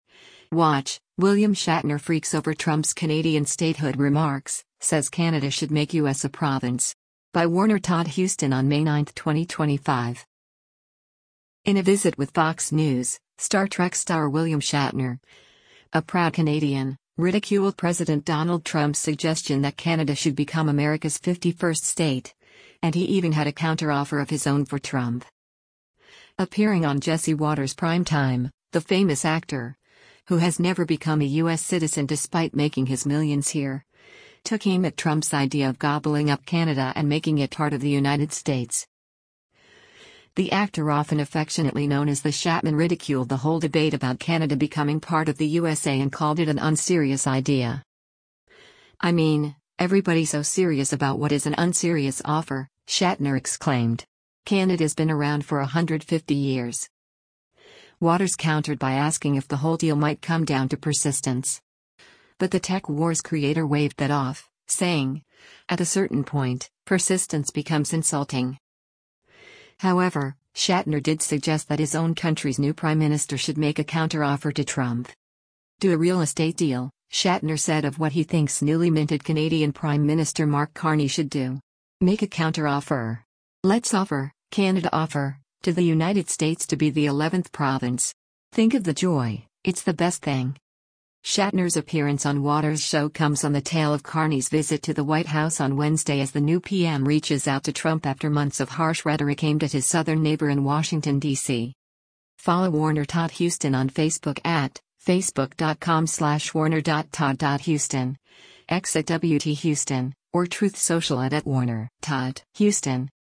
Appearing on Jesse Watters Primetime, the famous actor, who has never become a U.S. citizen despite making his millions here, took aim at Trump’s idea of gobbling up Canada and making it part of the United States.
“I mean, everybody so serious about what is an un-serious offer,” Shatner exclaimed. “Canada’s been around for a hundred fifty years!”
Watters countered by asking if the whole deal might come down to “persistence.” But the Tek Wars creator waved that off, saying, “At a certain point, persistence becomes insulting!”